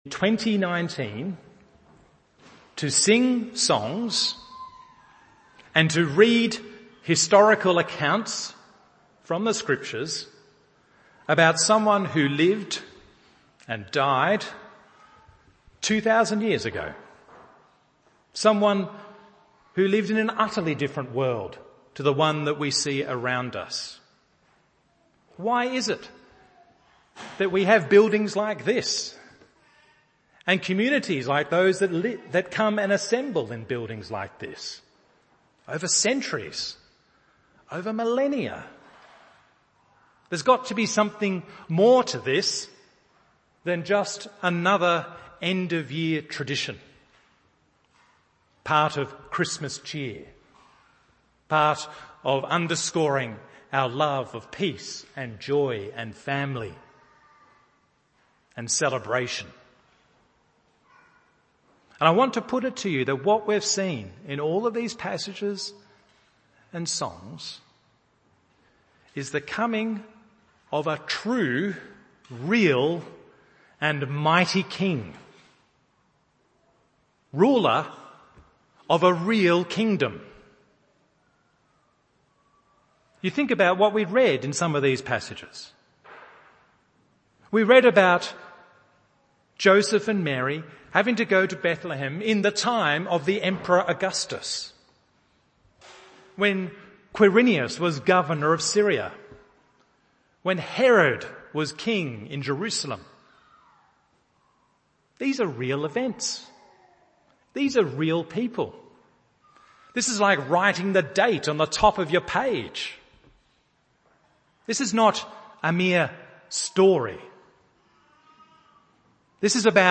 Christmas Carol Service – 2019